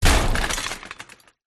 Звуки стены
Звук разбитого предмета о стену